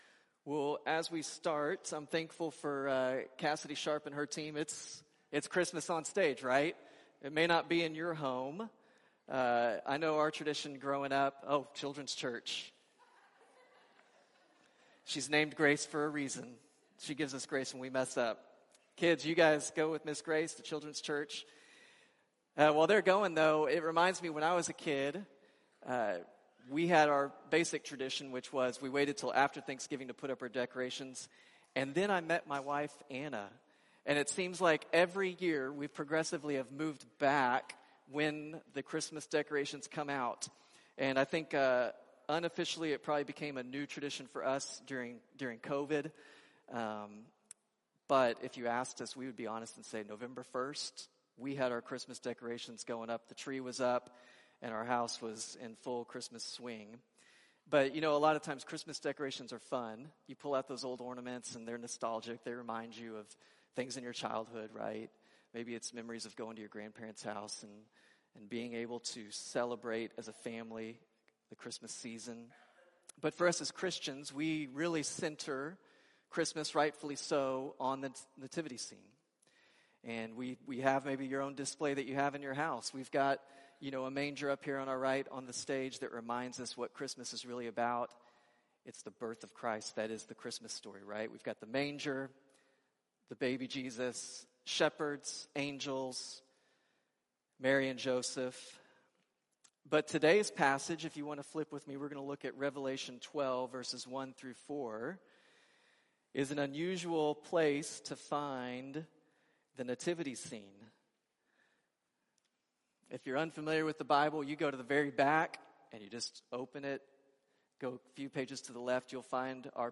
1 Sunday Service 51:06